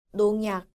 • nongyak